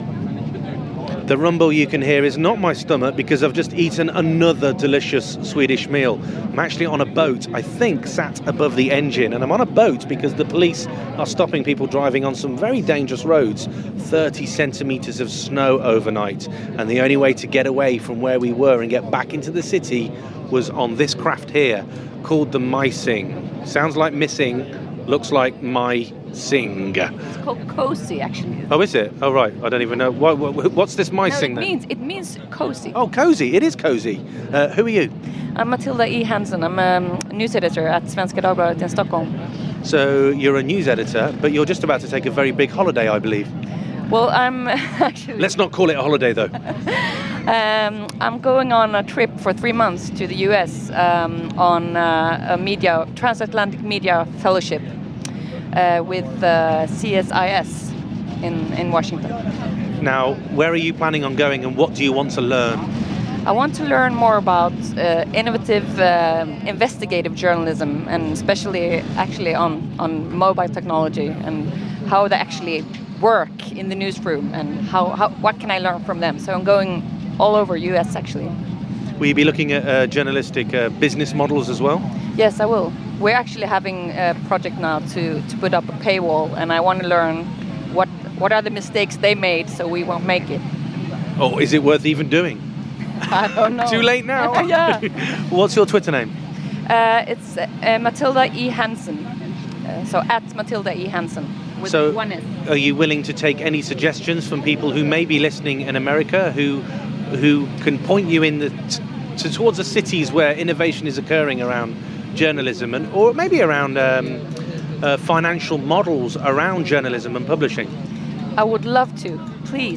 Sat on a boat